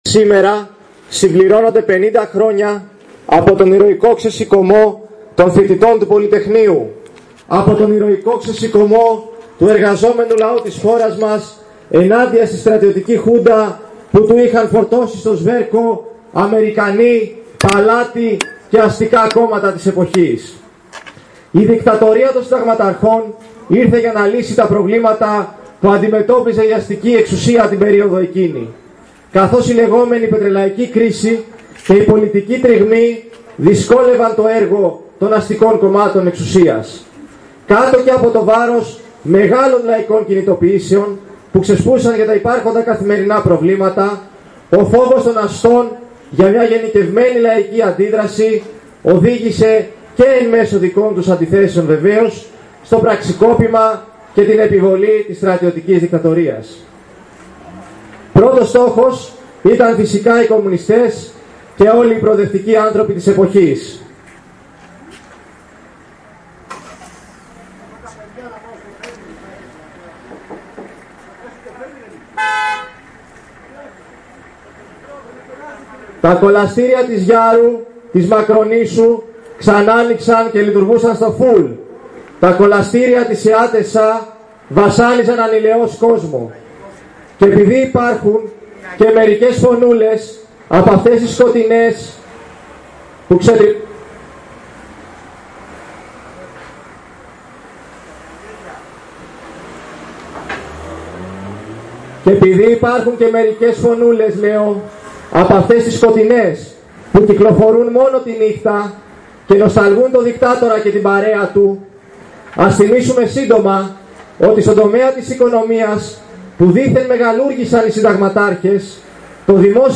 Συγκέντρωση και πορεία για τα 50 χρόνια του Πολυτεχνείου